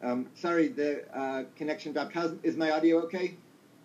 So I compressed some audio (Neil's voice of course) that I clipped from previous weeks of fab academy, you can download the original .m4a files down here: